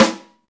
SNR XEXTS02L.wav